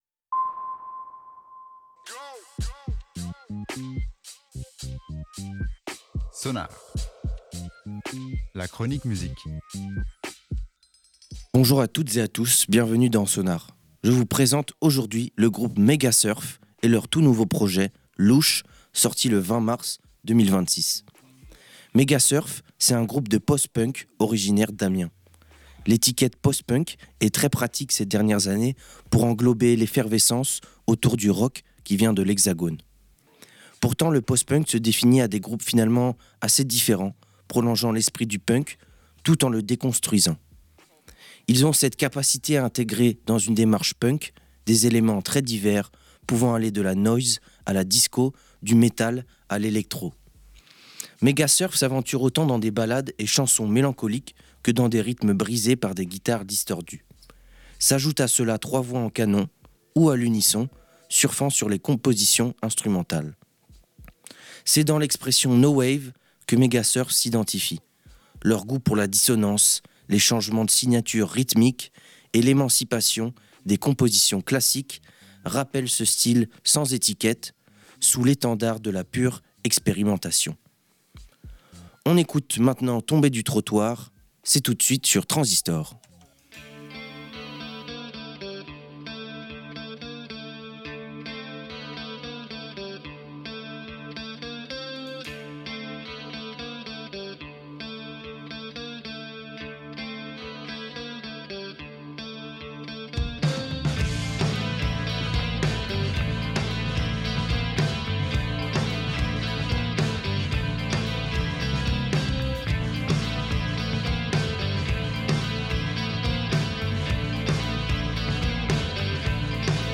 post punk